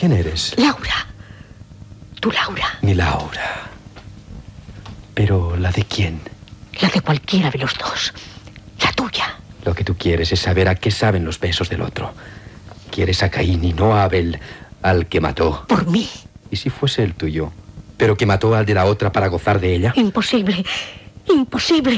Fragment de la versió radiofònica de l'obra "El otro" (1926) de Miguel de Unamuno.
Ficció
FM